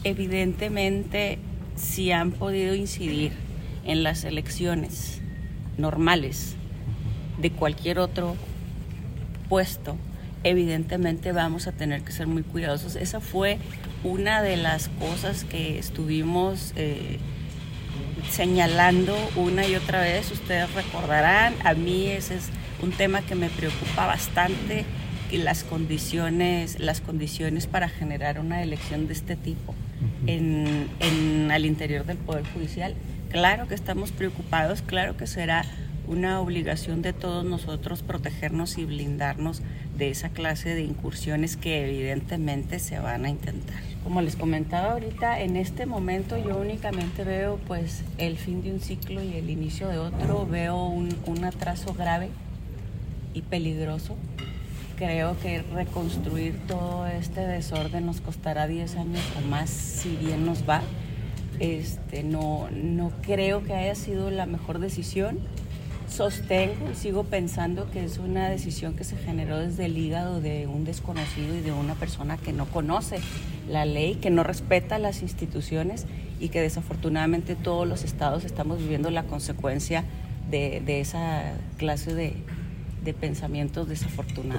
—¿Qué visualiza usted para México? —le preguntó la prensa durante un evento esta mañana.